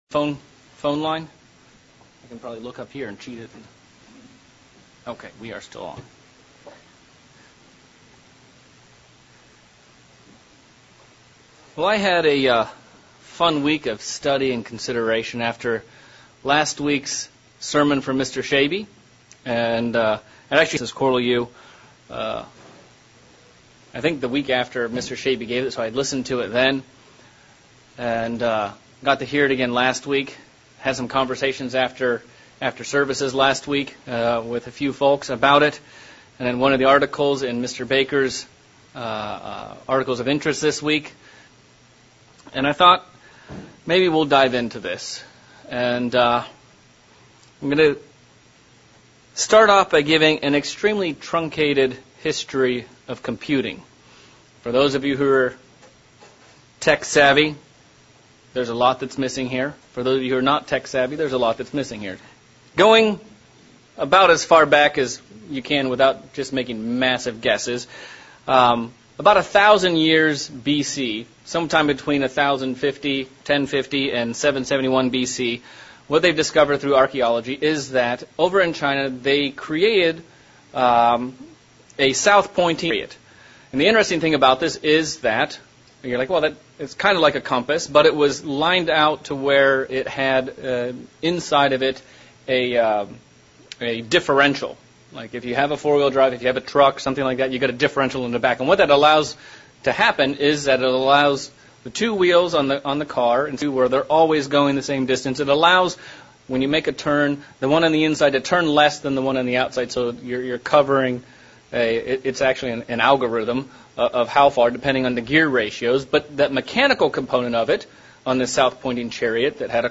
Sermon looking at the thoughts / ways of God vs. the ways of man and how much higher his ways are than ours. Can we as Christians begin to think and act like God?